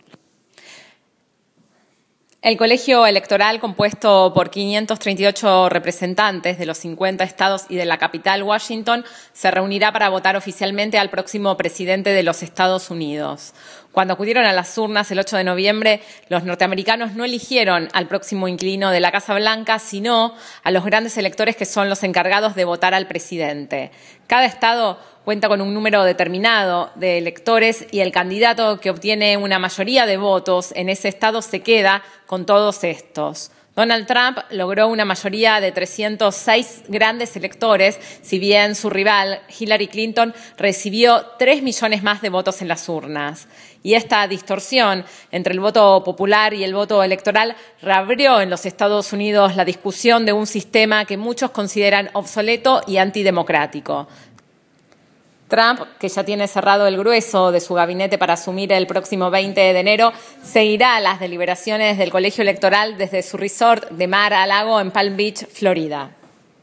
El informe